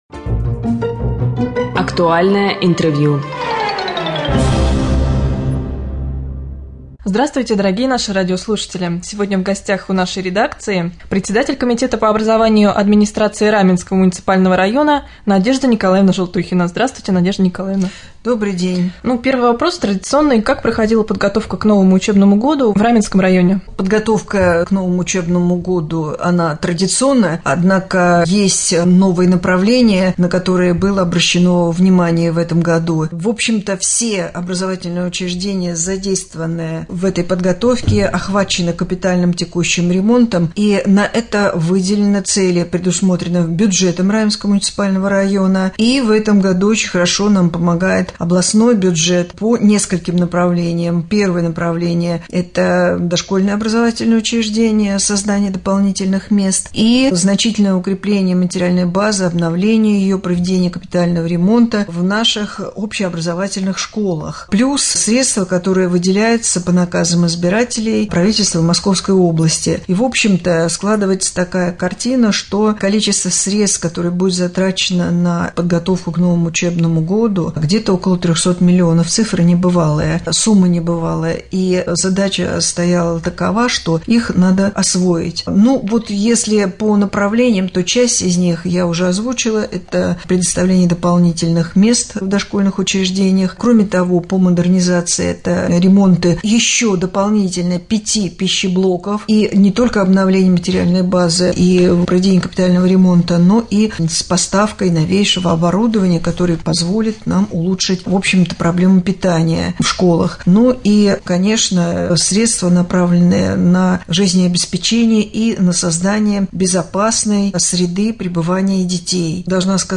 Председатель Комитета по образованию Н.Н. Желтухина в интервью Раменскому радио рассказала о подготовке к новому учебному году
1.-Интервью-с-Н.Н.Желтухиной.mp3